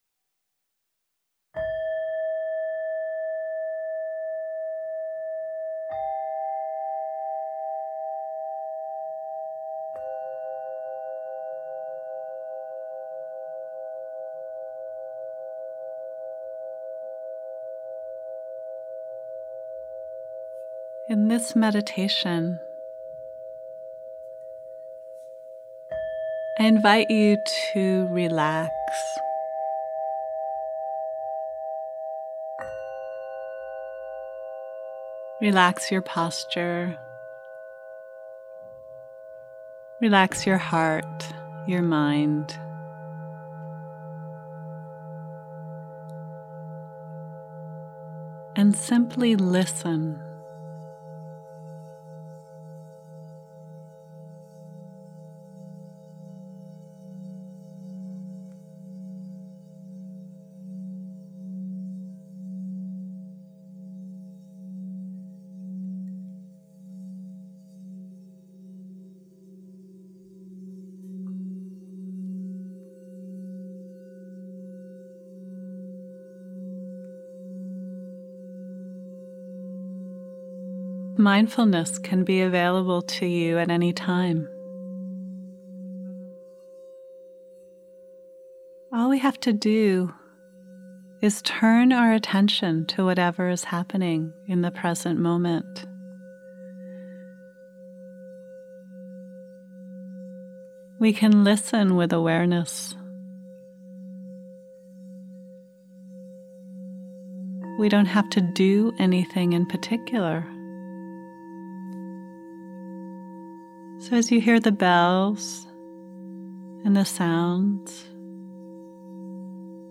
6. Tibetan Singing Bowls (8 mins)
06_Tibetan_Singing_Bowls.mp3